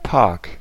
Ääntäminen
Ääntäminen France Tuntematon aksentti: IPA: /ʒaʁ.dɛ̃.py.blik/ Haettu sana löytyi näillä lähdekielillä: ranska Käännös Ääninäyte 1.